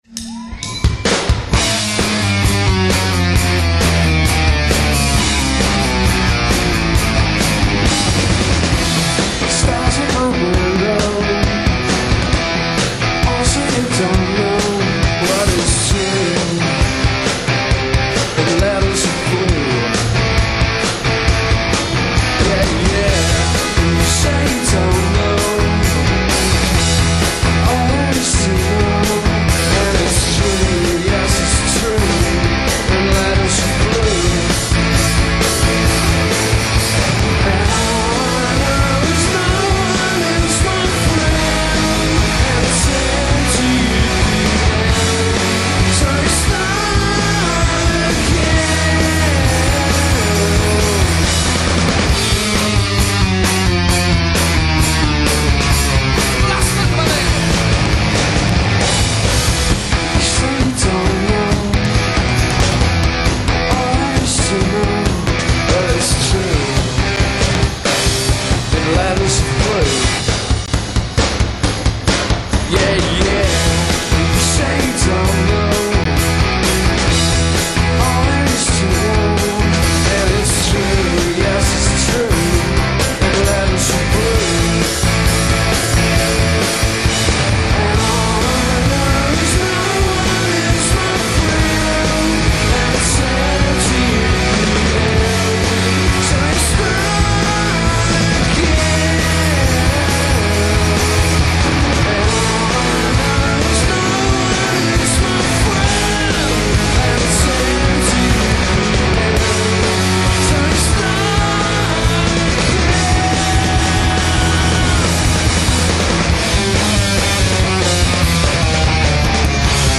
Complex arrangements and a nod to bent minds.
English psychedelic pop band